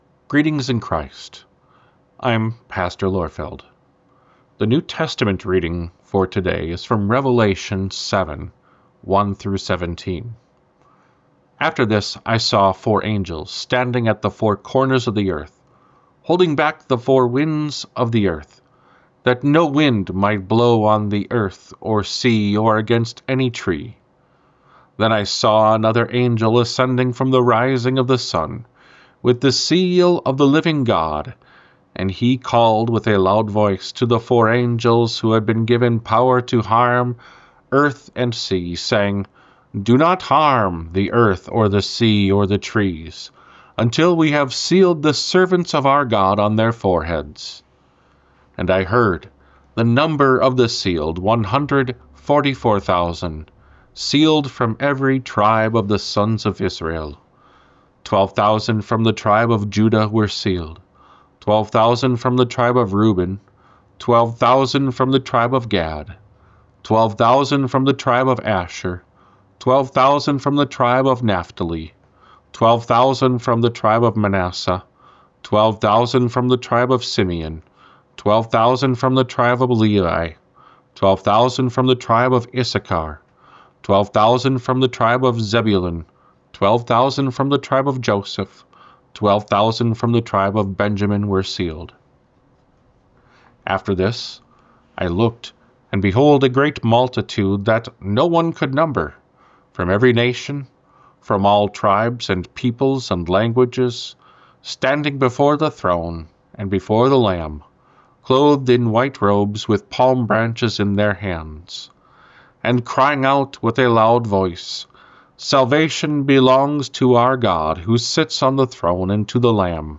Morning Prayer Sermonette: Revelation 7:1-17
Hear a guest pastor give a short sermonette based on the day’s Daily Lectionary New Testament text during Morning and Evening Prayer.